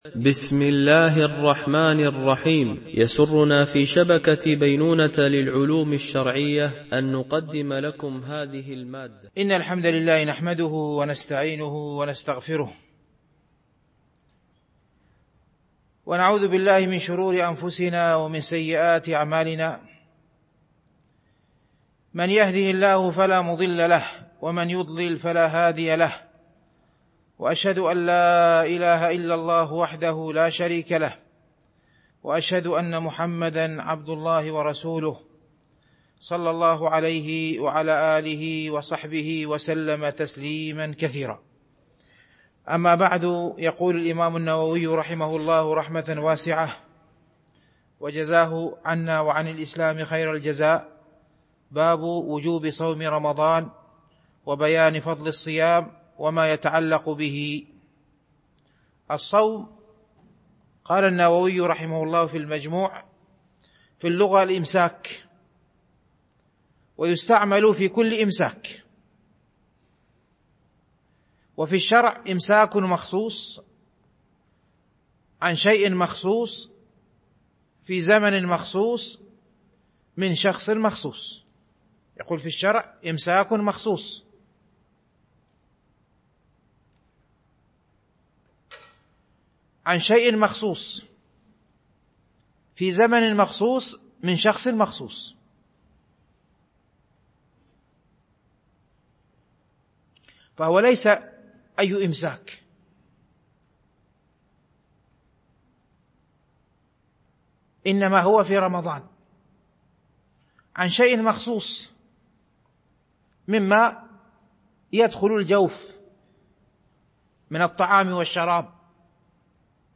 شرح رياض الصالحين – الدرس 318 ( الحديث 1223 )